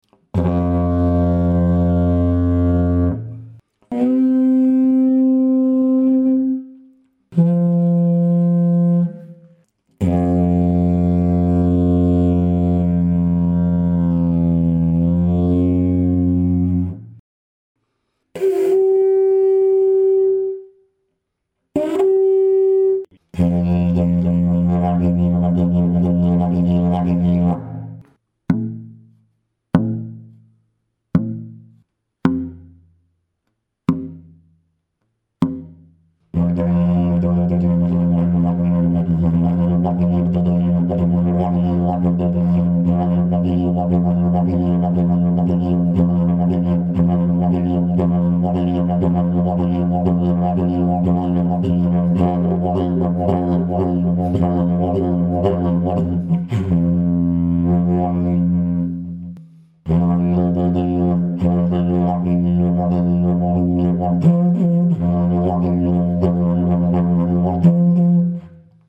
is a smaller didgeridoo with a pitch of F2, violet-blue with a slightly ceramic-metallic sheen, ochre-siena on the inside, weighs 2.3kg, has a very easy response, requires little air, and has a strong fundamental tone.
F2 (-30, +50) // F3 (-20, +10) / C ±10 / G-40
is a technical sample to show the overtones, the plop resonance and the range to pull the basic key